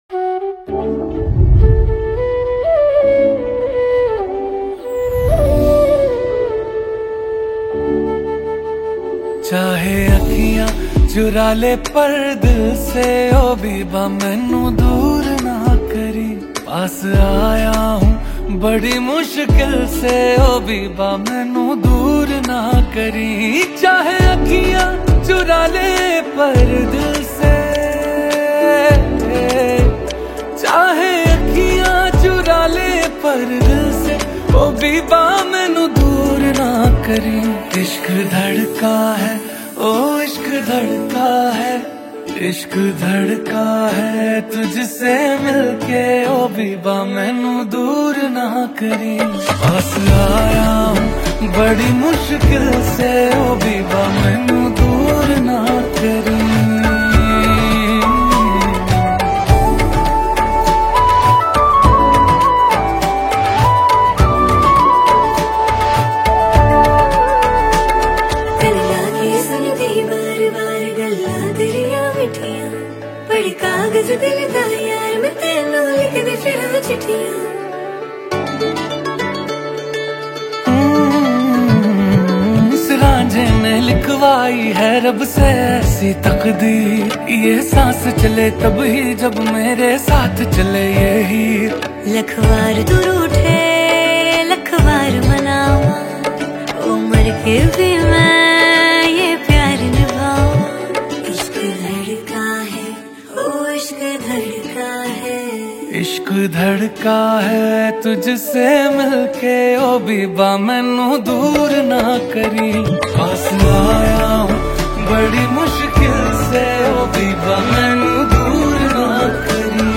contemporary and melodious
Bollywood Music